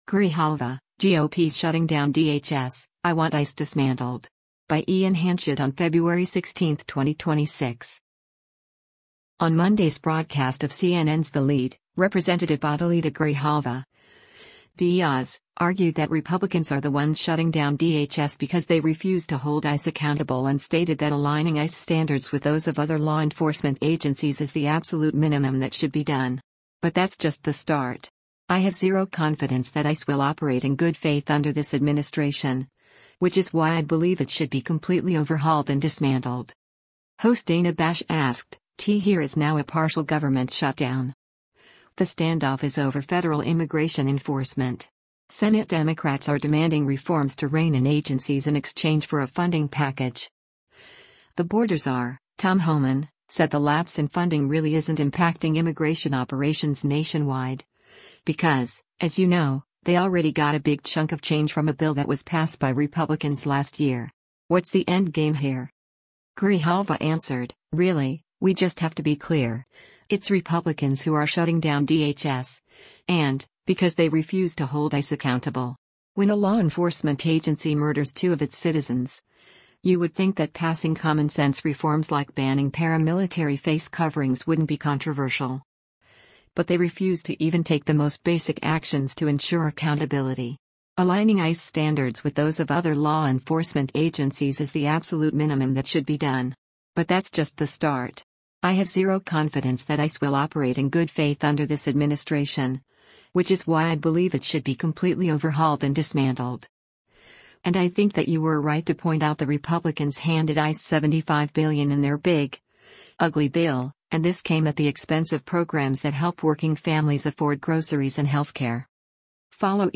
On Monday’s broadcast of CNN’s “The Lead,” Rep. Adelita Grijalva (D-AZ) argued that Republicans are the ones shutting down DHS “because they refuse to hold ICE accountable” and stated that “Aligning ICE standards with those of other law enforcement agencies is the absolute minimum that should be done. But that’s just the start. I have zero confidence that ICE will operate in good faith under this administration, which is why I believe it should be completely overhauled and dismantled.”